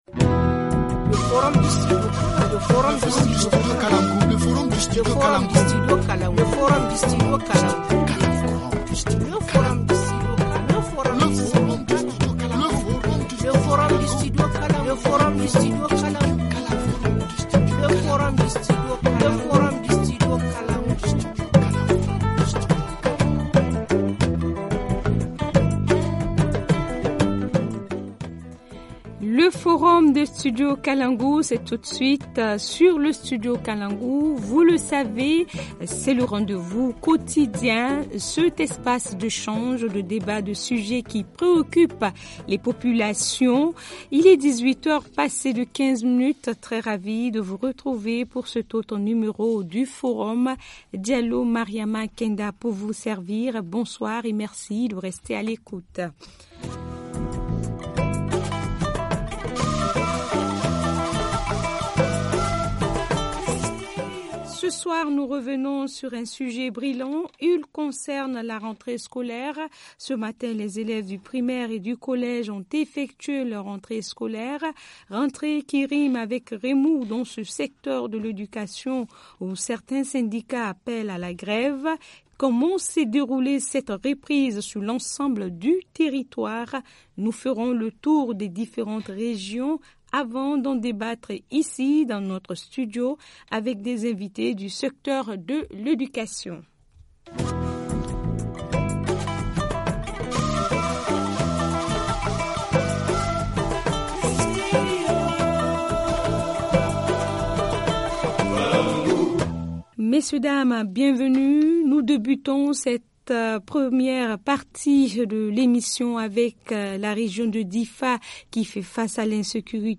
– Mahamane Lawalli Dan dano, gouverneur de la région de Diffa